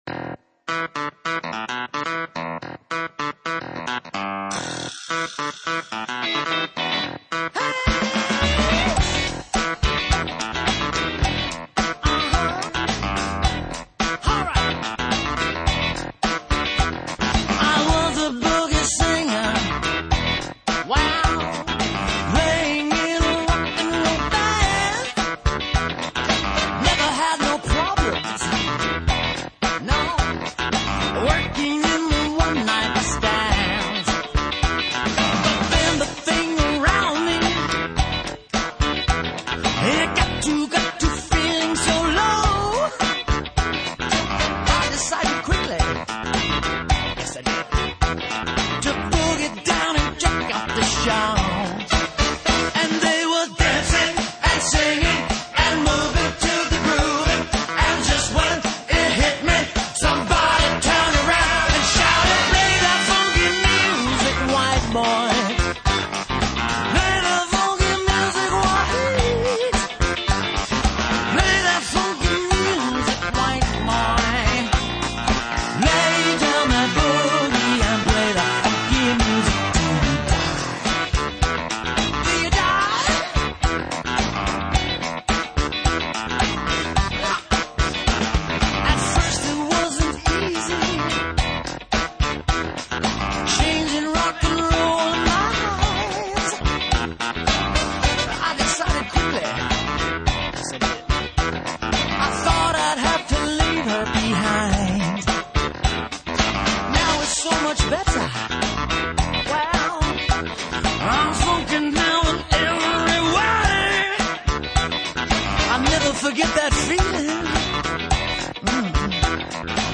Here are a few dance hits that are great at weddings.